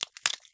ice crack 31.wav